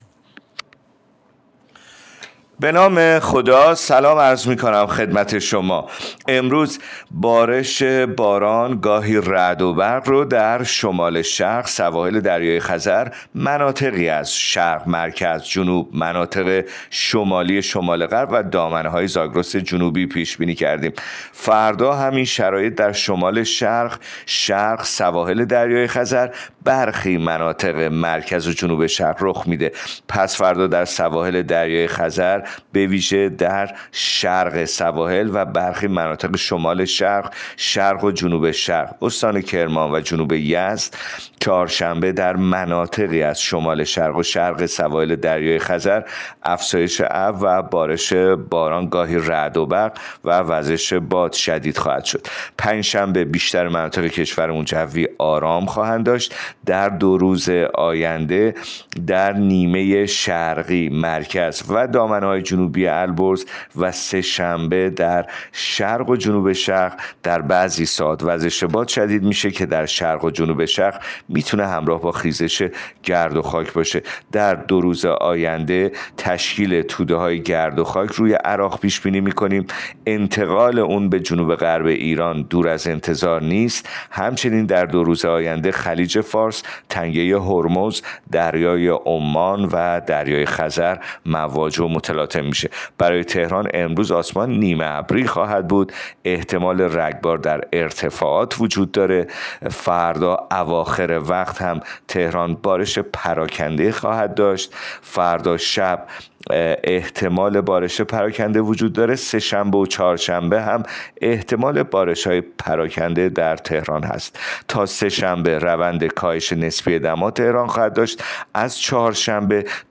گزارش رادیو اینترنتی پایگاه‌ خبری از آخرین وضعیت آب‌وهوای ۳ فروردین؛